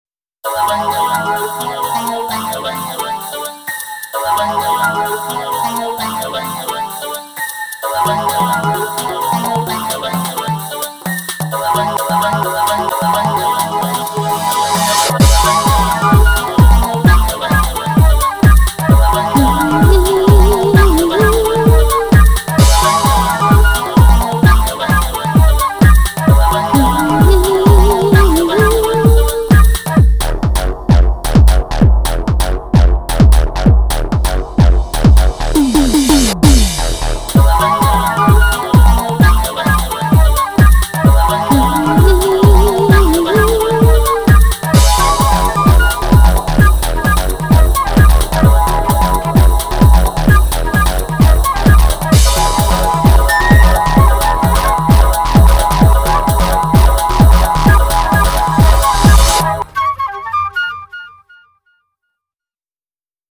BPM130
Audio QualityPerfect (High Quality)
A quite short song, sadly.